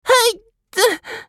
少年系ボイス～戦闘ボイス～
【ダメージ（強）1】